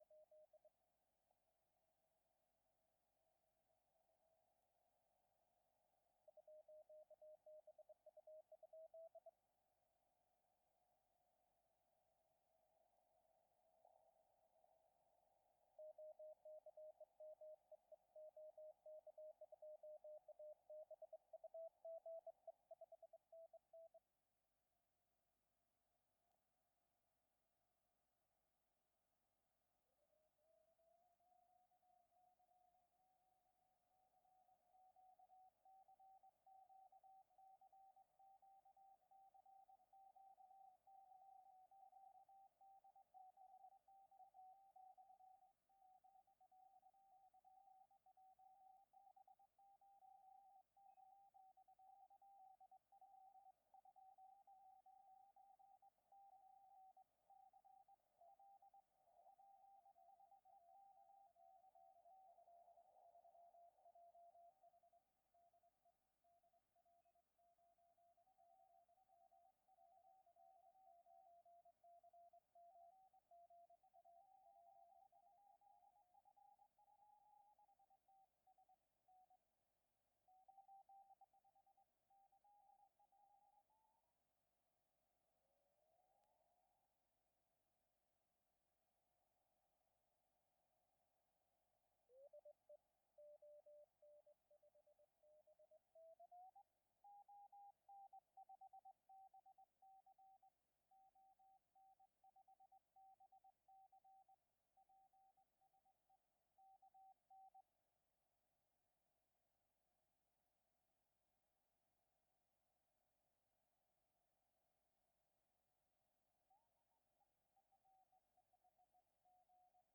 Прошивка 1.10.2. Диапазон 14 мГц. Режим CW. Тон 700 Гц. Фильтр 300Гц. PRE=OFF. RFG=0.
АРУ по-прежнему внезапно и сильно бьёт по ушам сигналами очень громких станций. Это продемонстрировано на записи ниже.
Сделал запись эфира с аудиовыхода на линейный вход рекордера.
Гораздо лучше стали читаться сигналы станций расположенные на одной частоте: нет той жуткой хрипящей "каши". В конце второй минуты будьте аккуратнее: сигнал мощной станции сильно бьёт по ушам. На 4-й минуте увеличивал RFG на максимальный уровень.
Послушал внимательно записи телеграфа. Опять появились вопросы. Почему сигналы такие "грязные"?